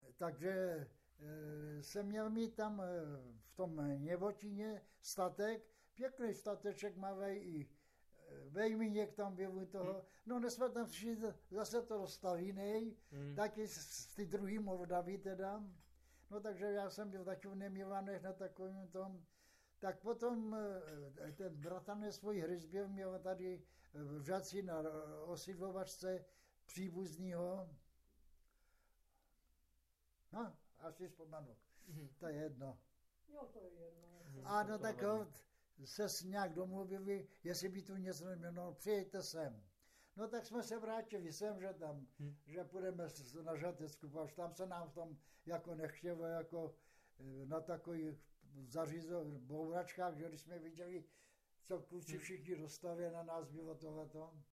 z vyprávění pamětníka
v Postoloprtech